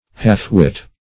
Half-wit \Half"-wit`\ (-w[i^]t`), n.